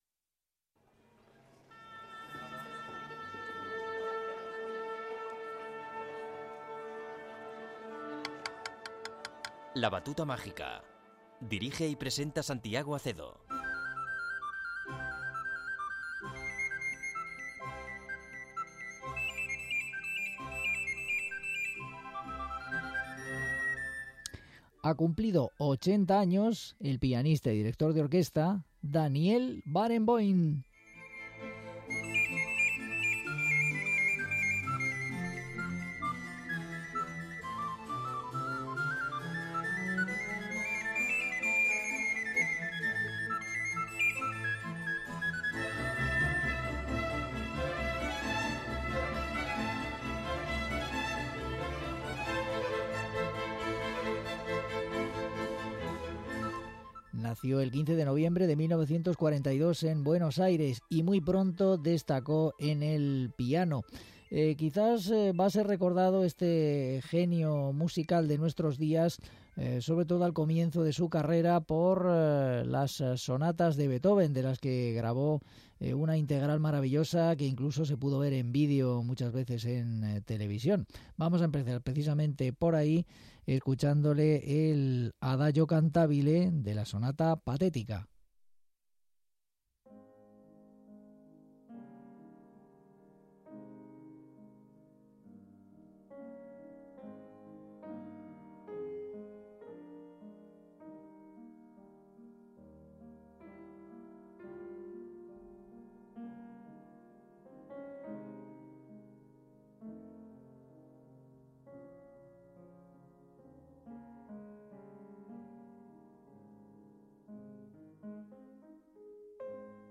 pianista y director
Andante del Concierto para Piano nº 21
Andante de la Sonata en Fa menor op. 5